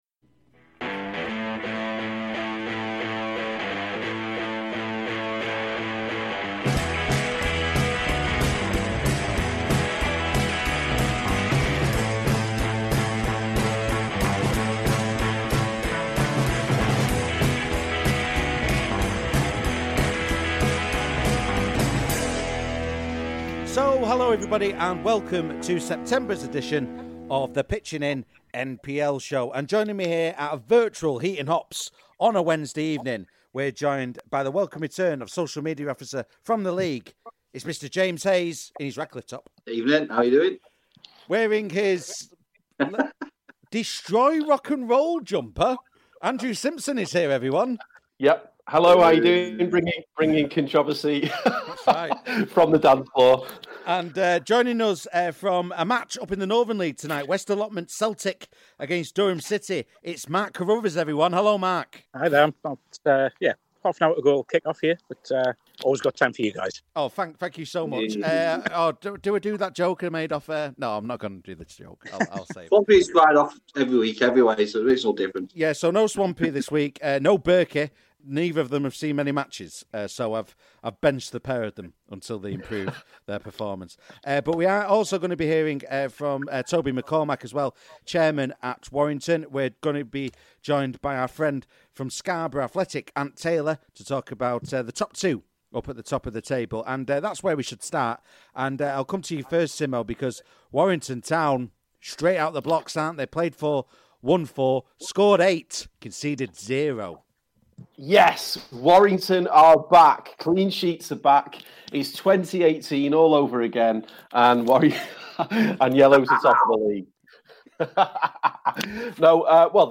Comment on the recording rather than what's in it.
This programme was recorded at a virtual Heaton Hops on Wednesday 7th October 2020.